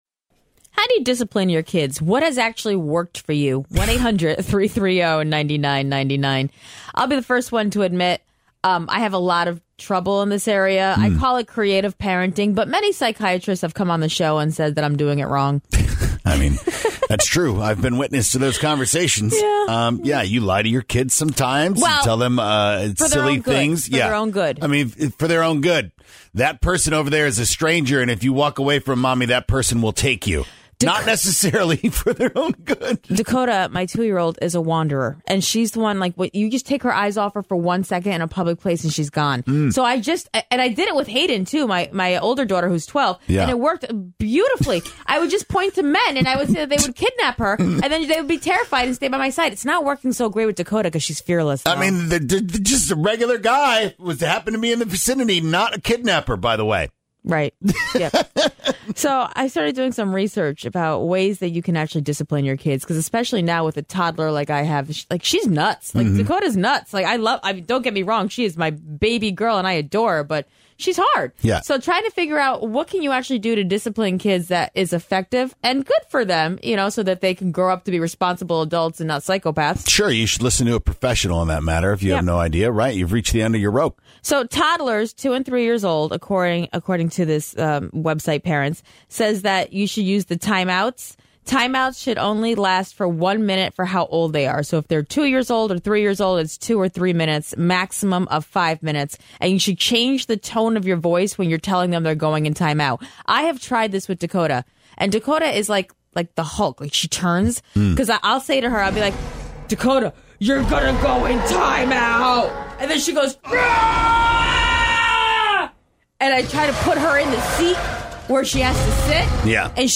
What is the most effective way to correct behavior? Your calls were... insightful.